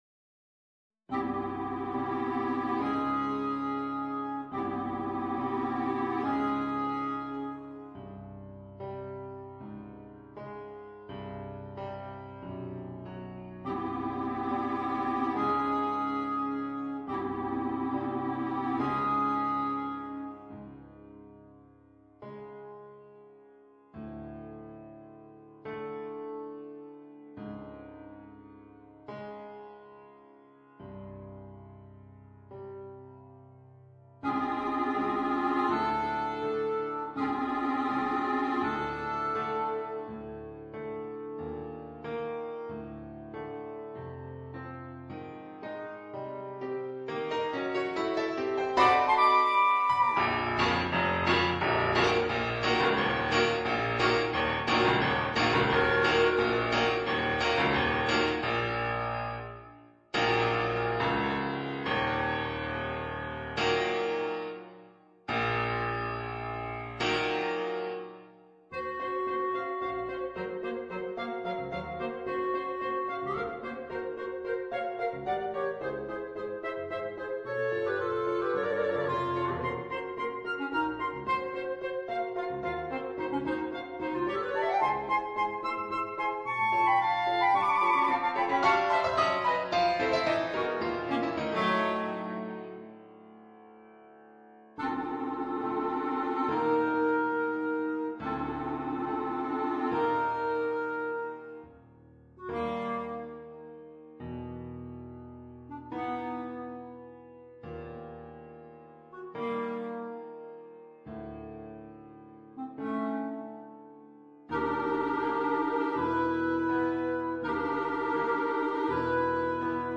per 2 clarinetti e pianoforte